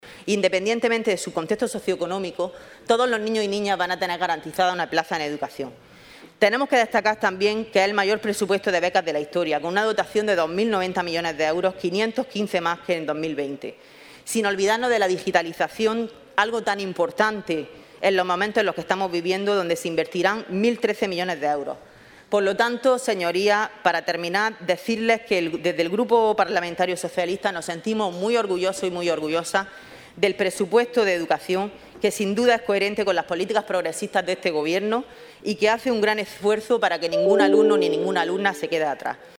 La senadora por el PSOE de Almería, Inés Plaza García, ha puesto en valor el incremento “histórico” de la inversión en Educación para 2021 que ha plasmado el Gobierno de España en los Presupuestos Generales del Estado (PGE) actualmente en tramitación en la Cámara Alta. Según ha destacado durante su intervención en el debate en la Comisión de Presupuestos del Senado de esta mañana, el Ejecutivo de Pedro Sánchez ha previsto una inversión de 5.697 millones de euros para Educación, lo que supone una subida del 139% respecto a enero de 2020.